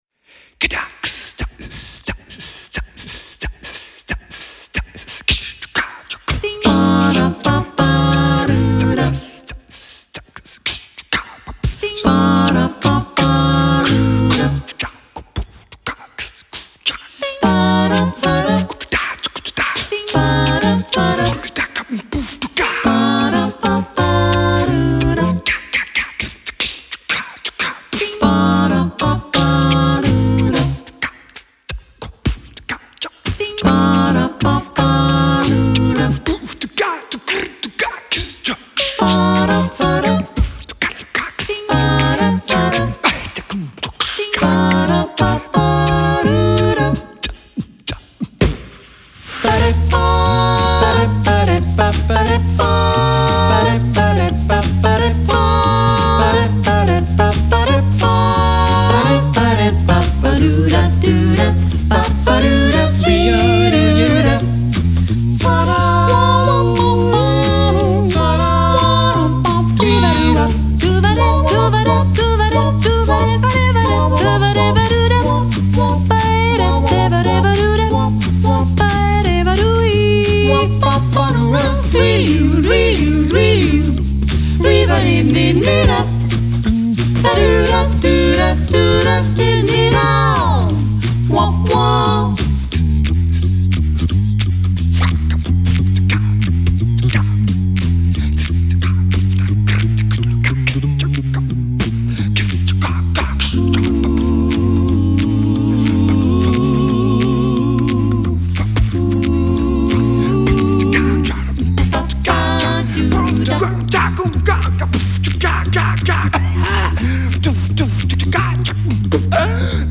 A-cappella-Gruppe
SATTB